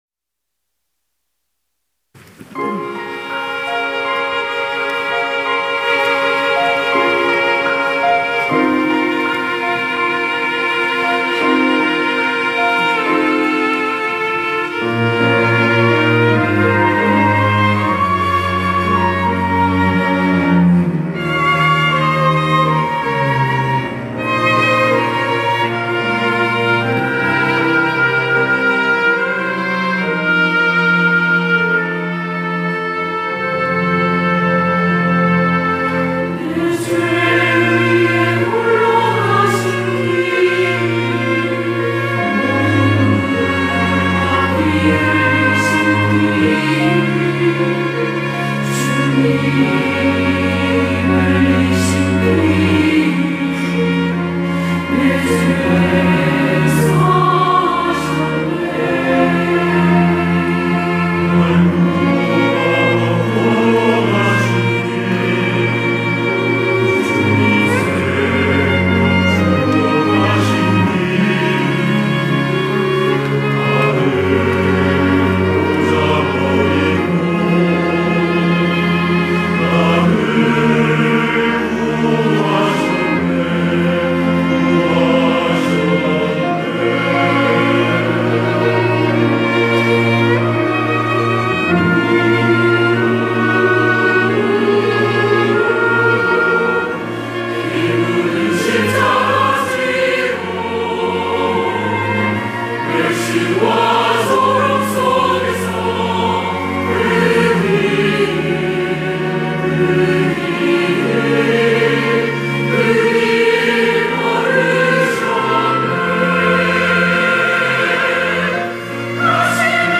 할렐루야(주일2부) - 그 길
찬양대